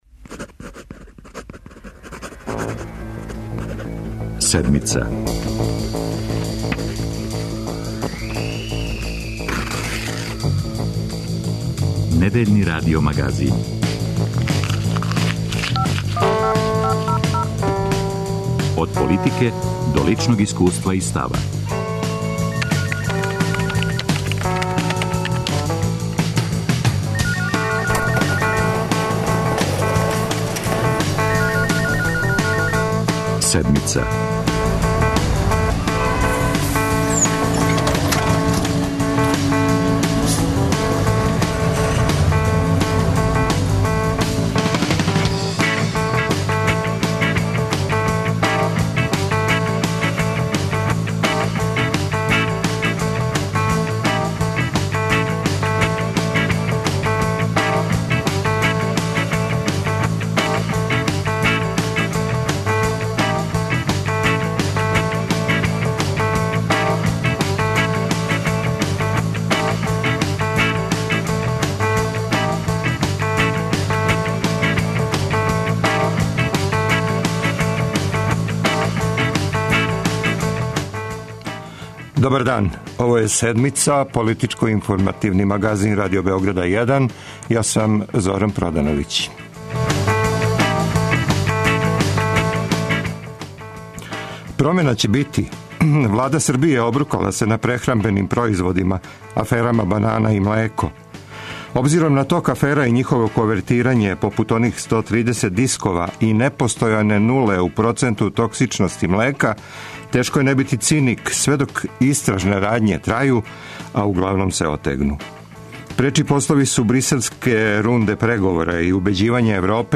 За Седмицу говоре потпредседница Социјалистичке парије Србије Дијана Вукомановић, портпаролка Демократске странке Александра Јерков и посланик Либерално демократске партије у парламенту Бојан Ђурић.